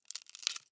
Звуки термитов
Звук, когда термит что-то грызет